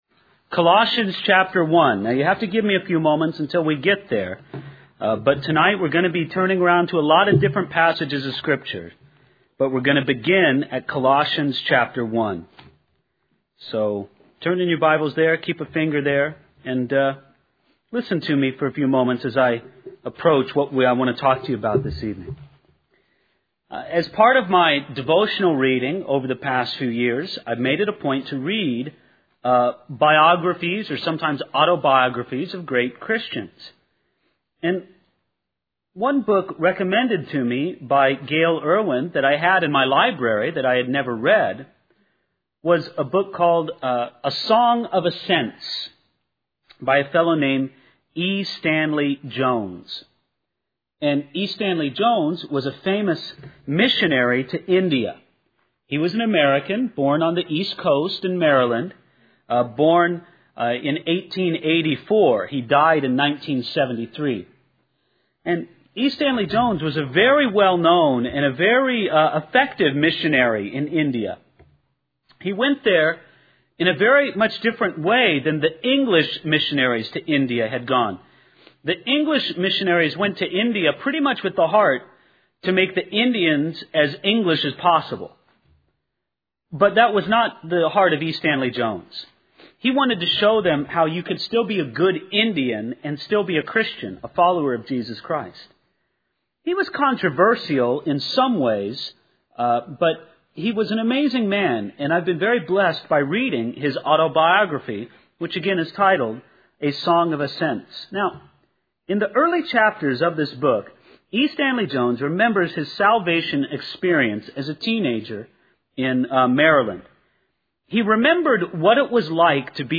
In this sermon, the speaker reflects on the transformation that occurred in their life when they encountered the grace of God.